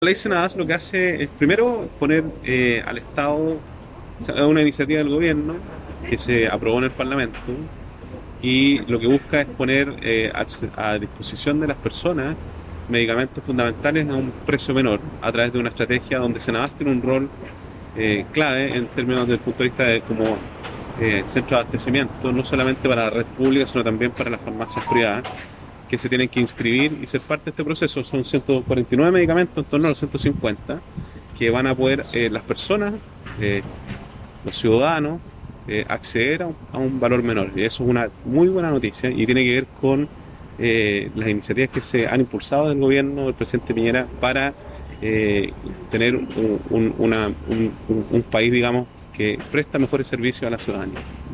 La máxima autoridad regional declaró que esta ley «es un tremendo avance para reducir el gasto del bolsillo de las familias asociado a medicamentos; ya que pone a disposición de la población un listado de farmacias que se van adhiriendo a la Ley, que en el caso de la región son 3 a la fecha, correspondientes a la farmacia Trébol de Cabrero, Setop Pharma de Concepción y Step de Los Ángeles».
cenabast-01-intendente.mp3